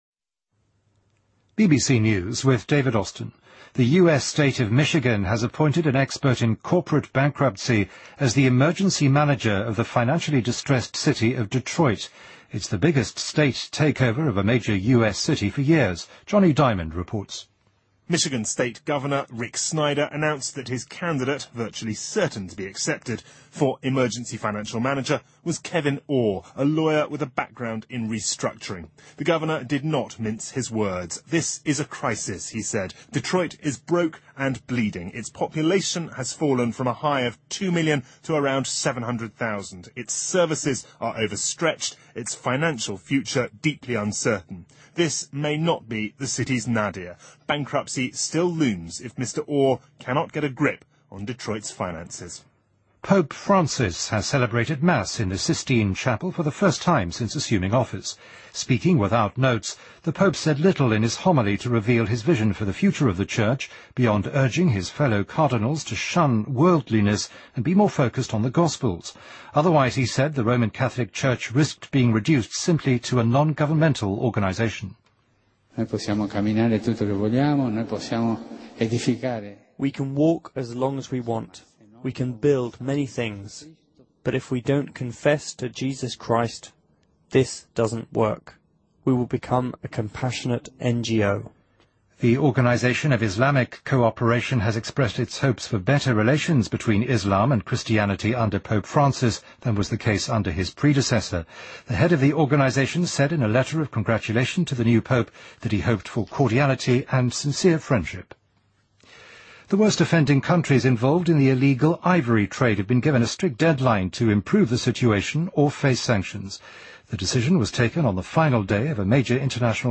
BBC news,2013-03-15